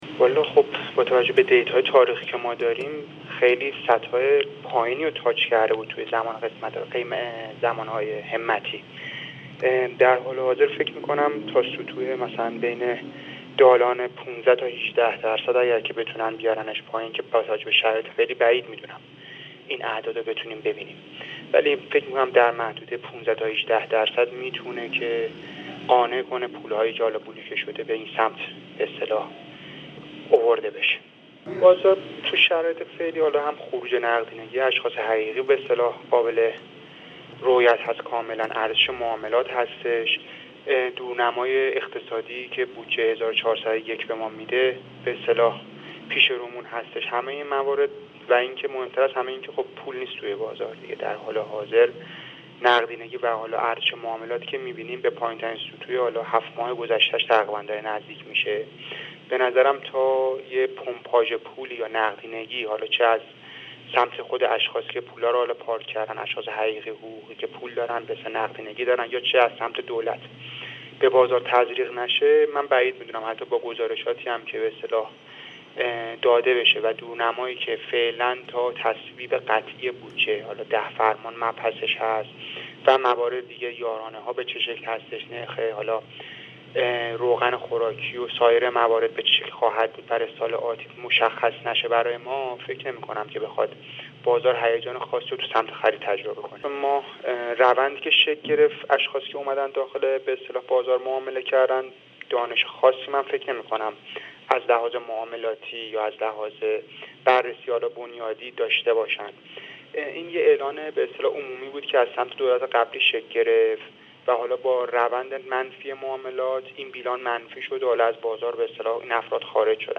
کارشناس بازار سرمایه؛